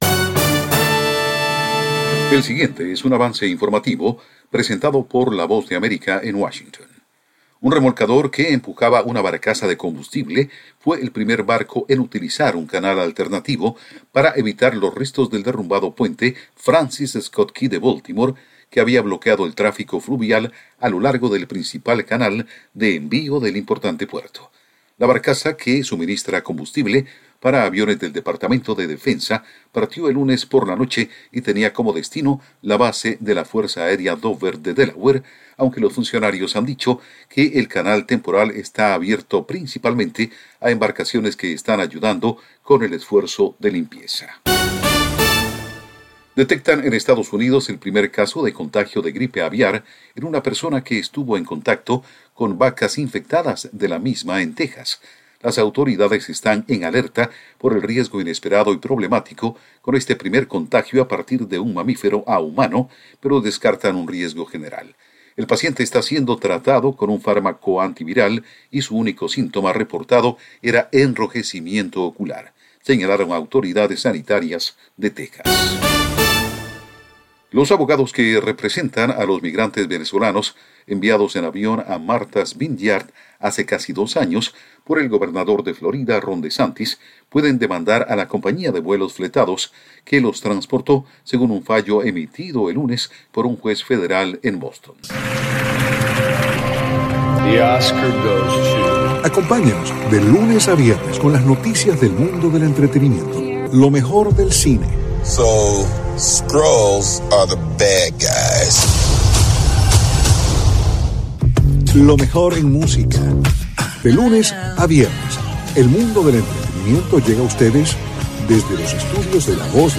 El siguiente es un avance informative presentado por la Voz de America en Washington